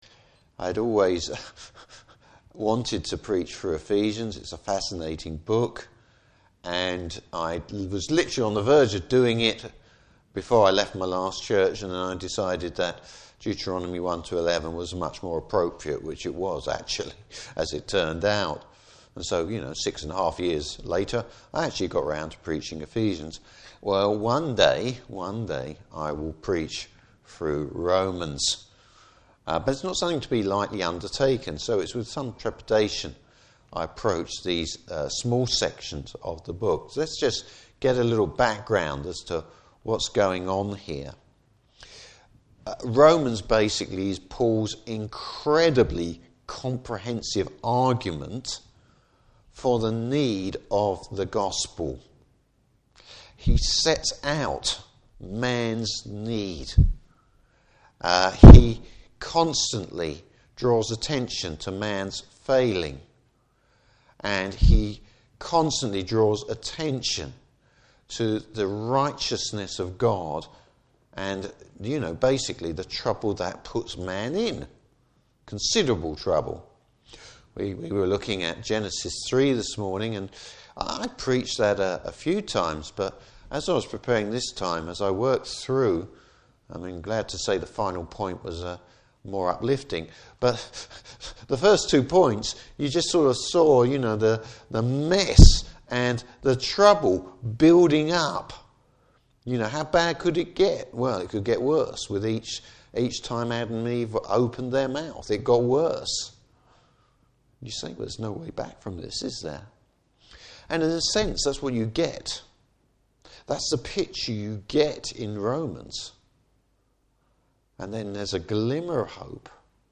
Service Type: Evening Service Bible Text: Romans 13:8-14.